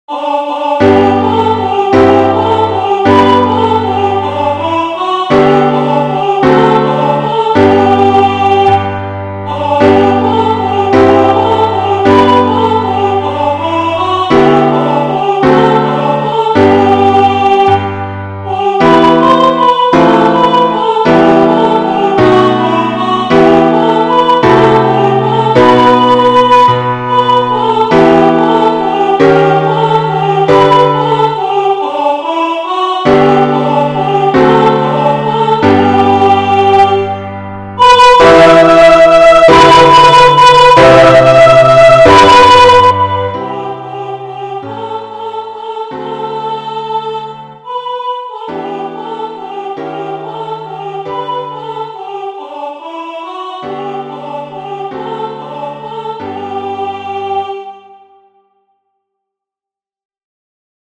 Not too fast.